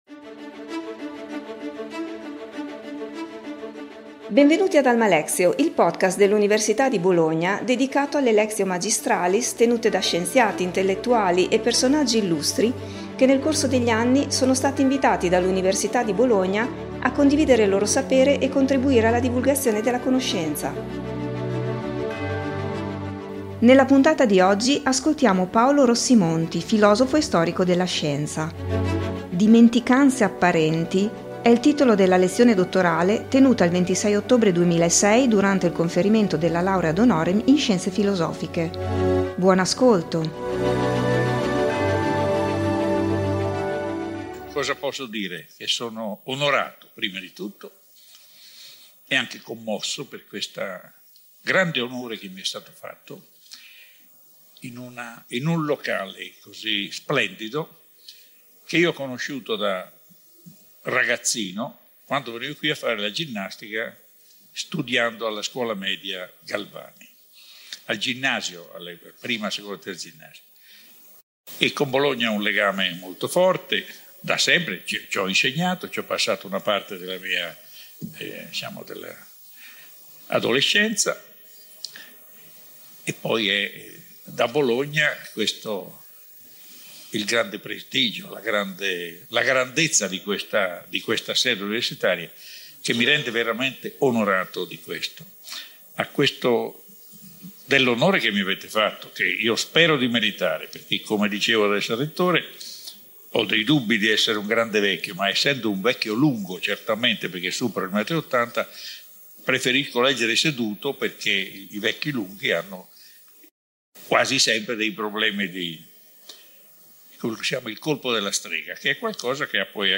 Paolo Rossi Monti, filosofo e storico della scienza, ha tenuto la sua Lectio Magistralis sulle apparenti dimenticanze della staoria il 26 ottobre 2006 durante il conferimento della laurea ad honorem in Scienze filosofiche.